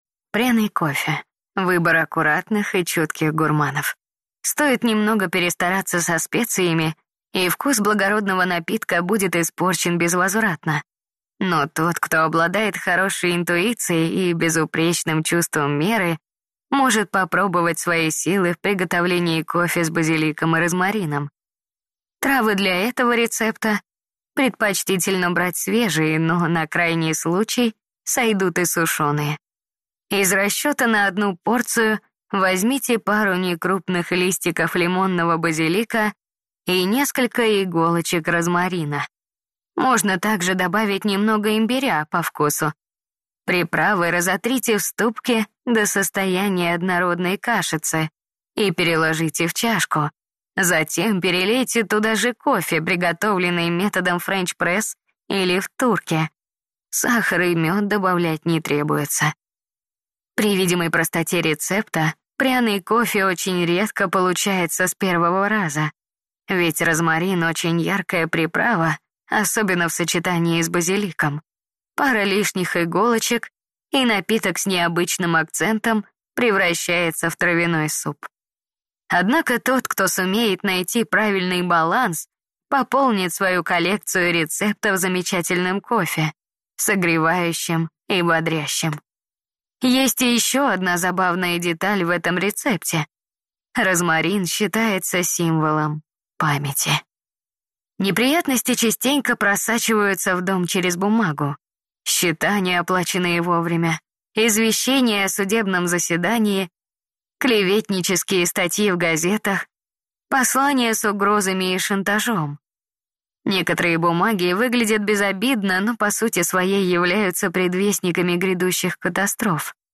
Аудиокнига Пряный кофе | Библиотека аудиокниг